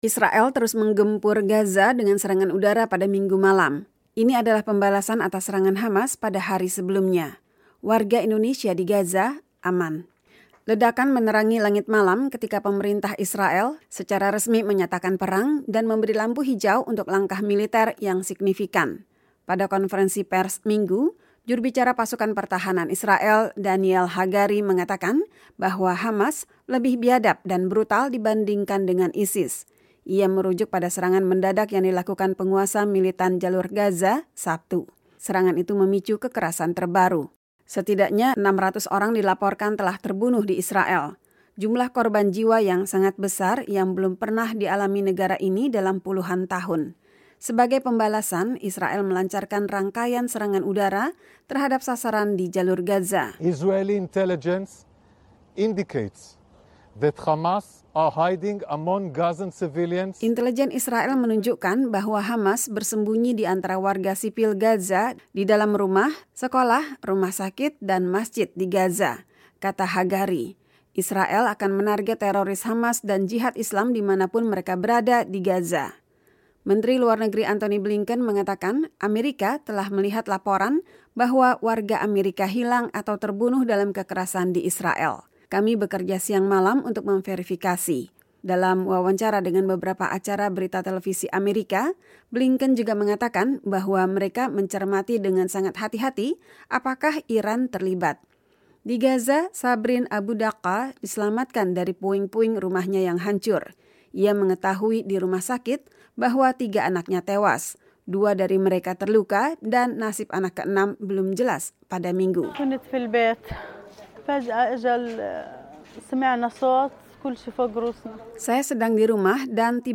Pada konferensi pers, Minggu, juru bicara Pasukan Pertahanan Israel, Daniel Hagari, mengatakan bahwa “Hamas lebih biadab dan brutal dibandingkan dengan ISIS.”